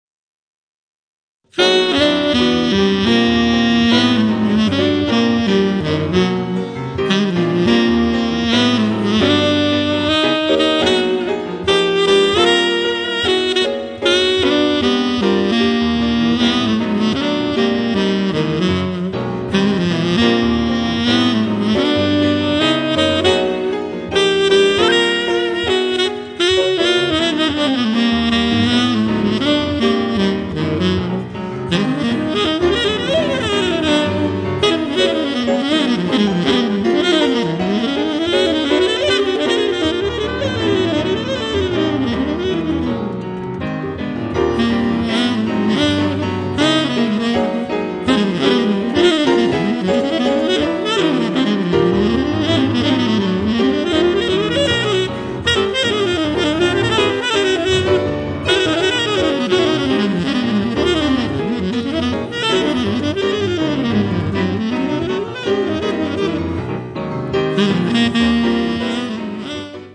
tenor sax
piano
Bass
Drums